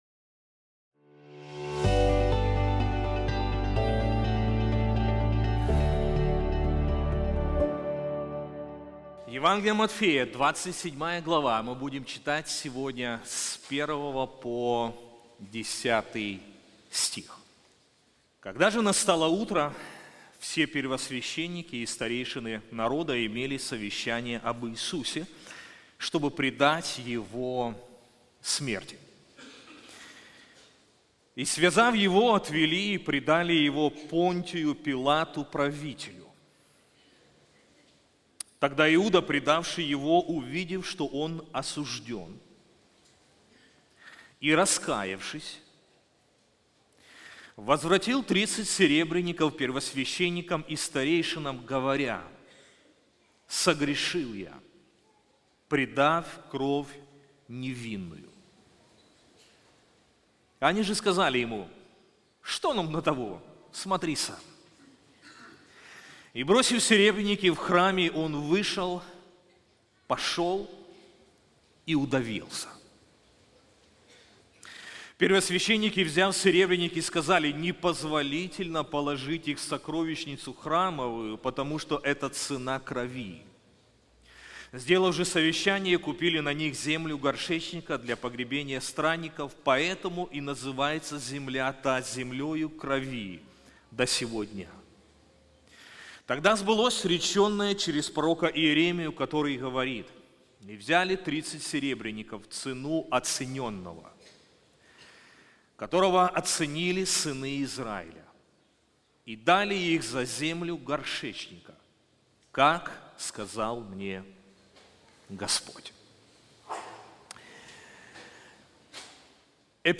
Проповеди